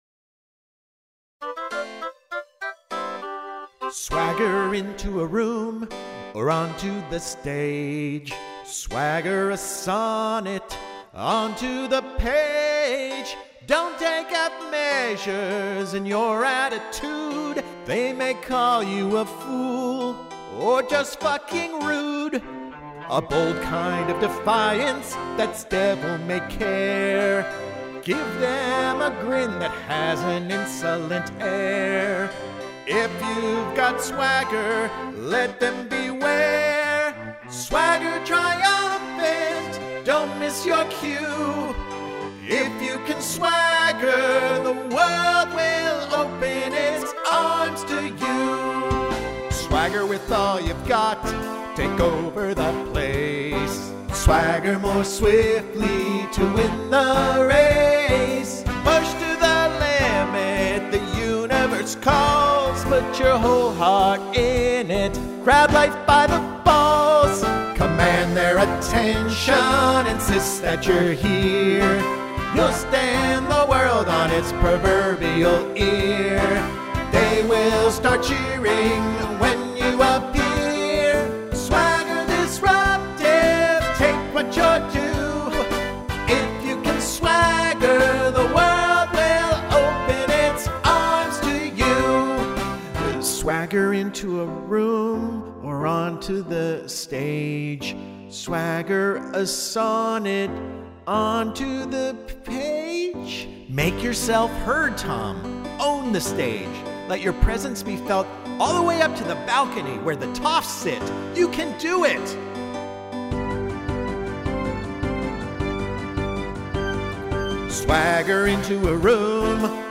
rousing title number